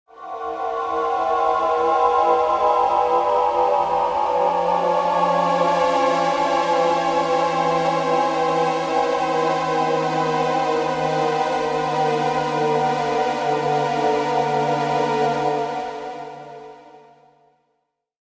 Hiệu ứng âm thanh Kinh dị và Hồi hộp